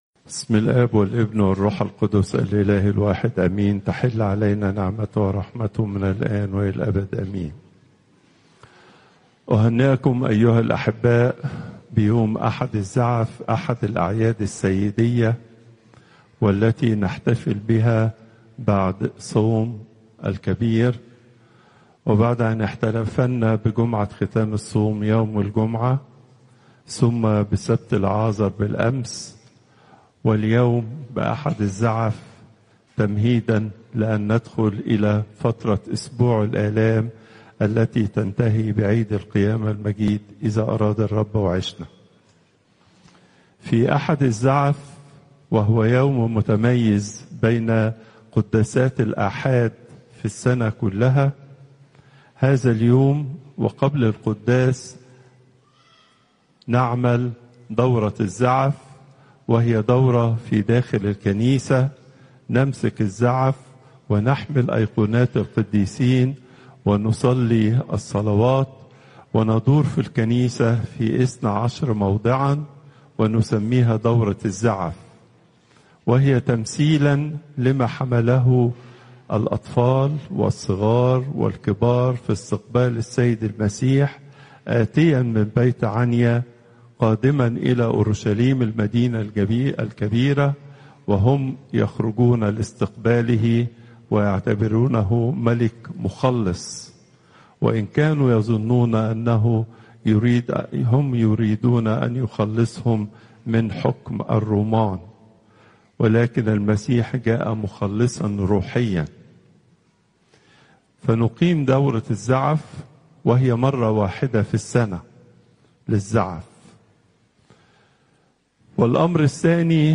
المحاضرة الأسبوعية لقداسة البابا تواضروس الثاني